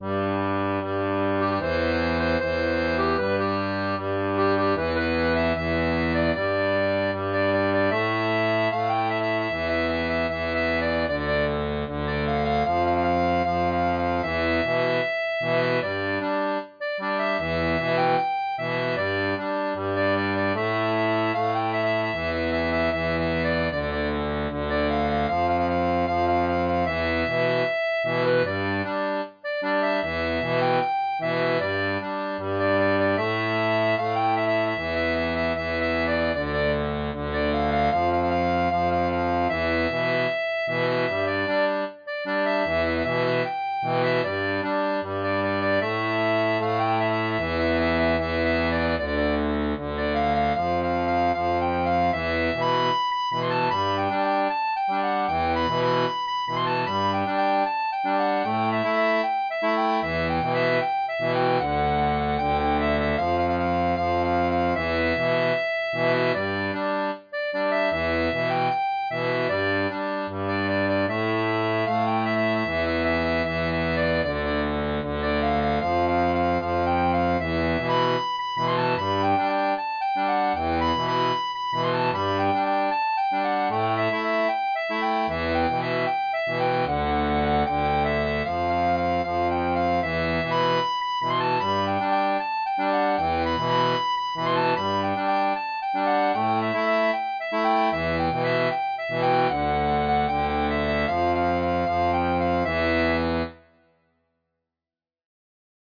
Version transposée en D
• une version pour accordéon diatonique à 3 rangs
Chanson française